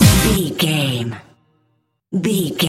Aeolian/Minor
Fast
drum machine
synthesiser
electric piano
bass guitar
conga